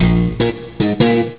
basse.zip 143 Ko 3 intermèdes musicaux joués à la basse.
Basse [cliquez pour écouter] 11 ko